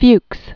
(fyks), Klaus Emil Julius 1911-1988.